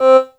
10SYNT01  -L.wav